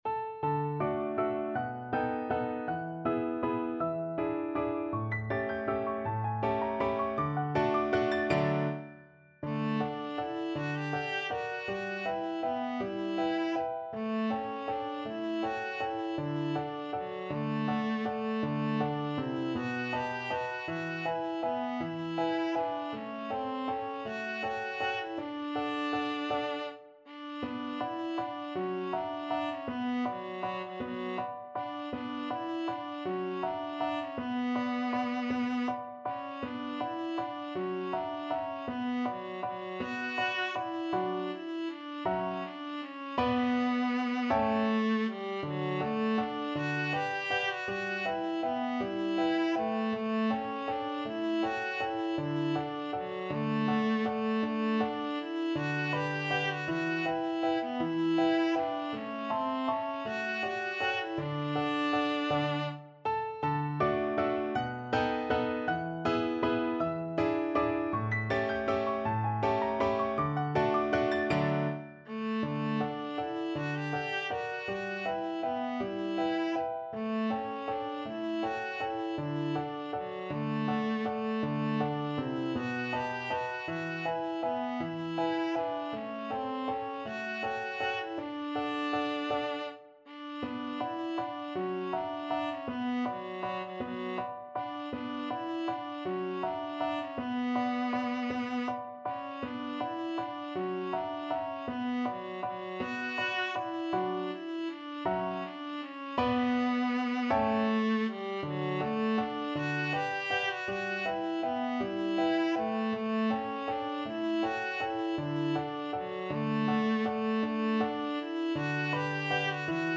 Viola
3/4 (View more 3/4 Music)
D major (Sounding Pitch) (View more D major Music for Viola )
~ = 160 Tempo di Valse
Traditional (View more Traditional Viola Music)